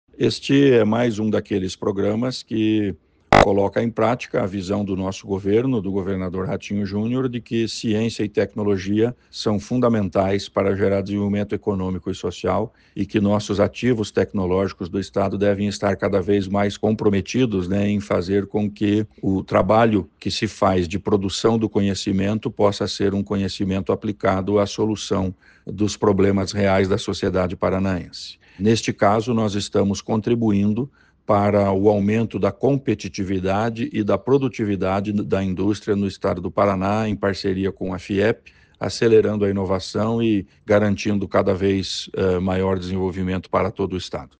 Sonora do secretário da Ciência, Tecnologia e Ensino Superior, Aldo Bona, sobre o programa Jornada da Ciência, Tecnologia e Inovação do Setor Industrial